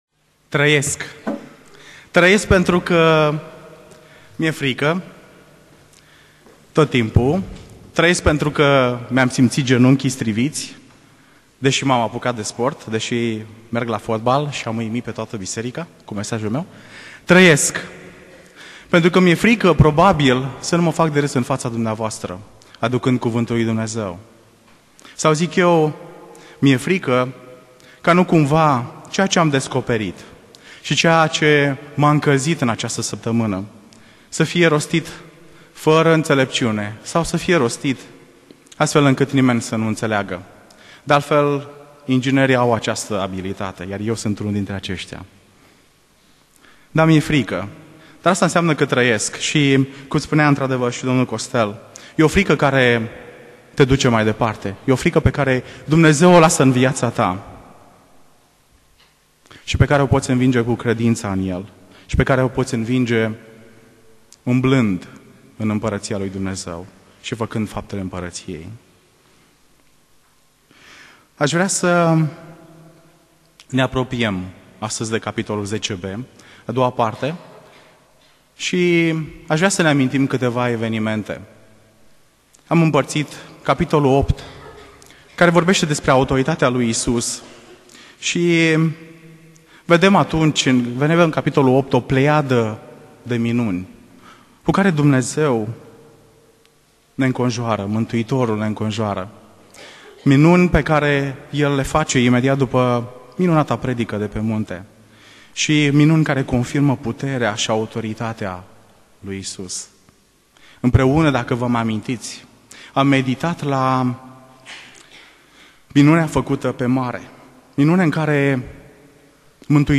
Predica Exegeza - Matei 10